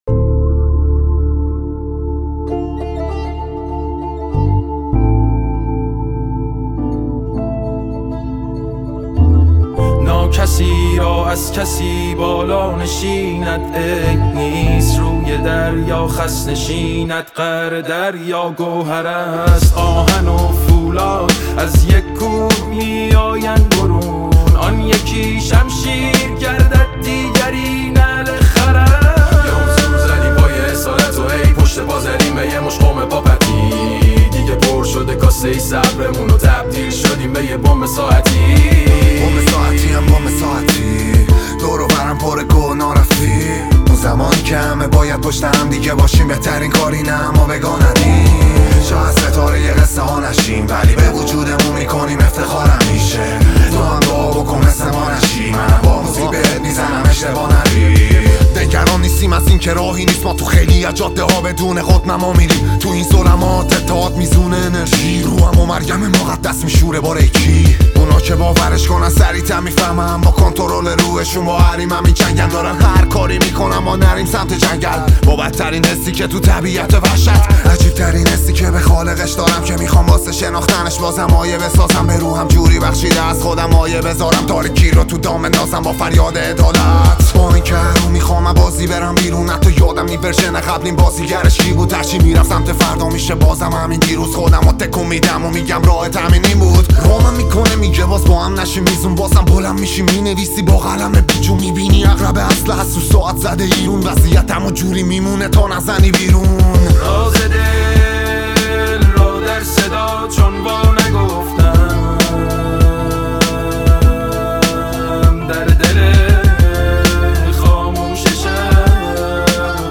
موزیک،رپ